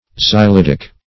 Search Result for " xylidic" : The Collaborative International Dictionary of English v.0.48: Xylidic \Xy*lid"ic\, a. (Chem.)